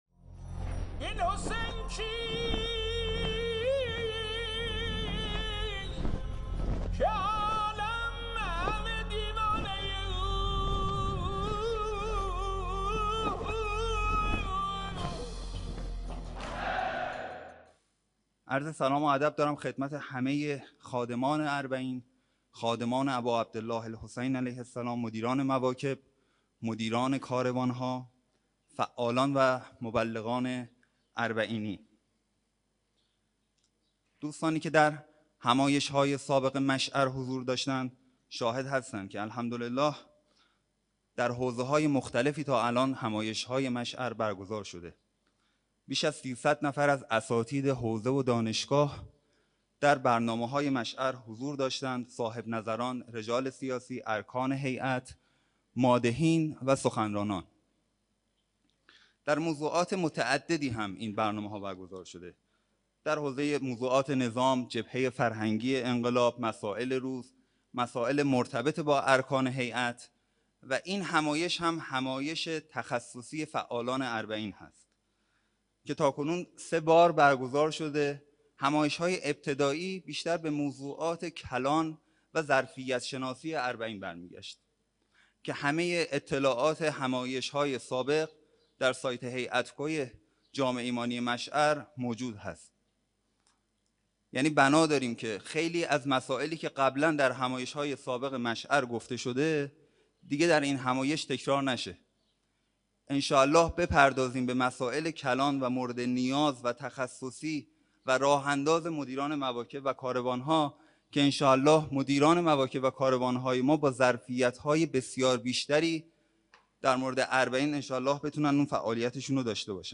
سخنرانی
در چهارمین همایش ملی هیأت های فعال در عرصه اربعین با موضوع معرفی برنامه های همایش